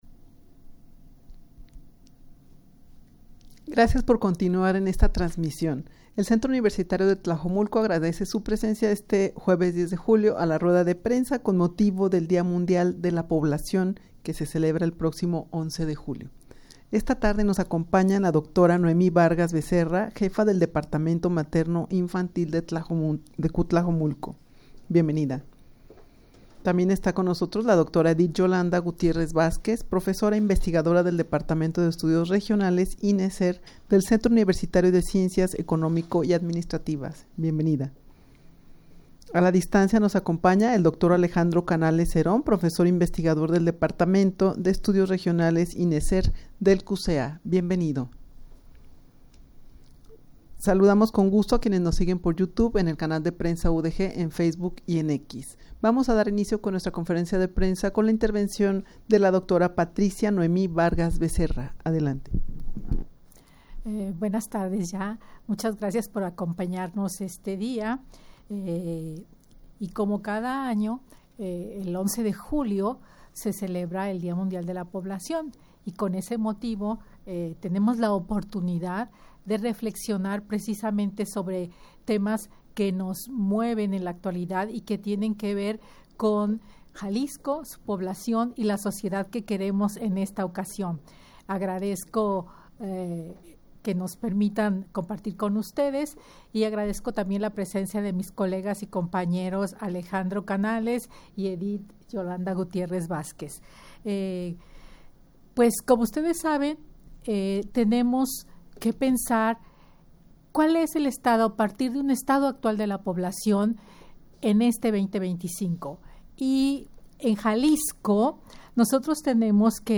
Audio de la Rueda de Prensa
rueda-de-prensa-con-motivo-del-dia-mundial-de-la-poblacion-que-se-celebra-el-proximo-11-de-julio.mp3